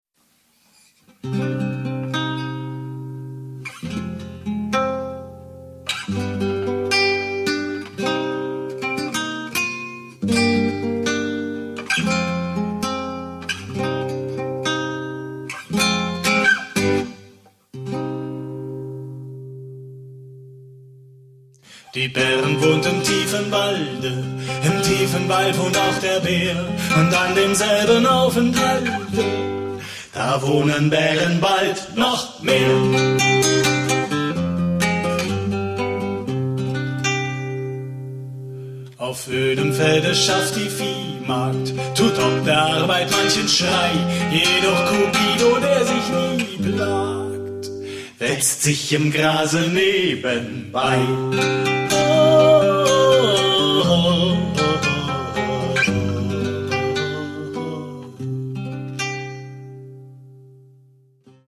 Gitarre, Flöte, Blockflöte, Klavier, Gesang